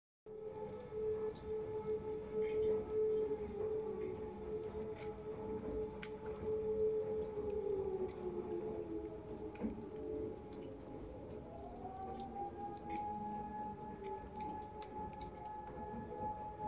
It's rainy. The sirens are going off again. The church faintly rings its bells in the distance.